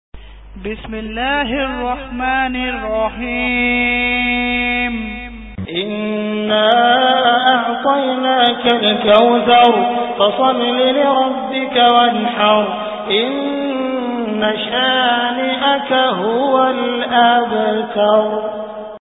Surah Al Kauthar Beautiful Recitation MP3 Download By Abdul Rahman Al Sudais in best audio quality.